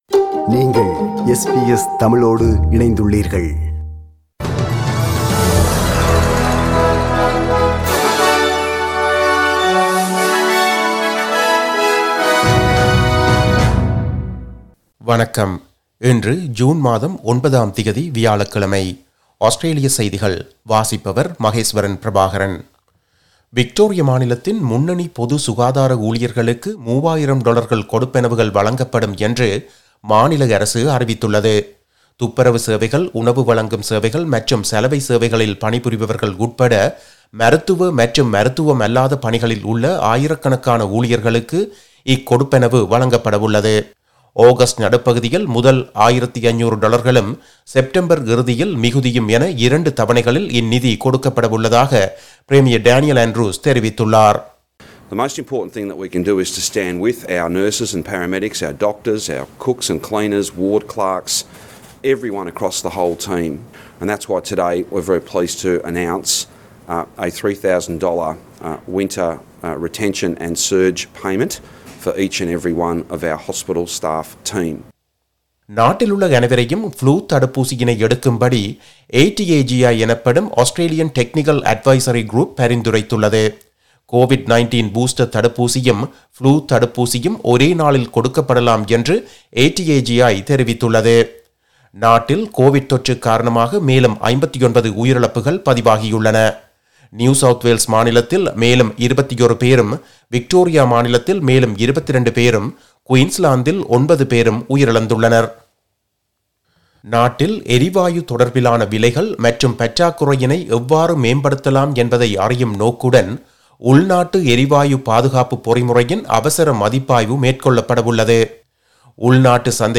Australian news bulletin for Thursday 09 June 2022.